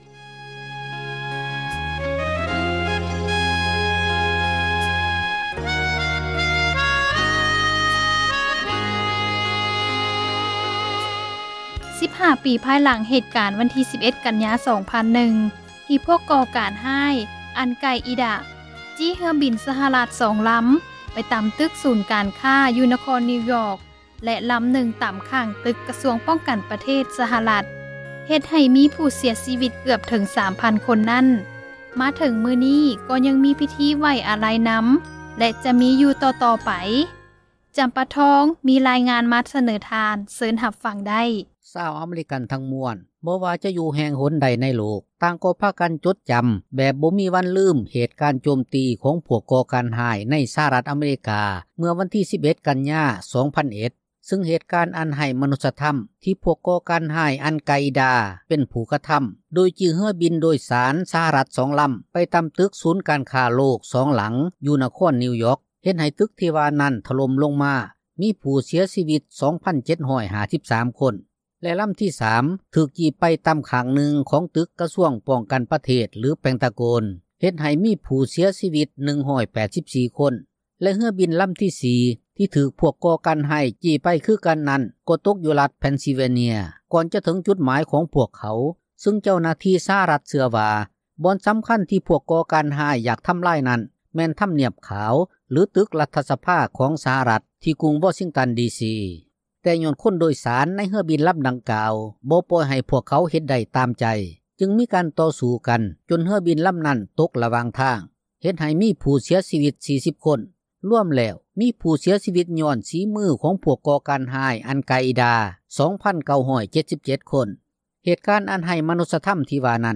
ມີຣາຍງານ ມາສເນີ ທ່ານ ເຊີນ ຮັບຟັງໄດ້.